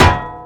slam.wav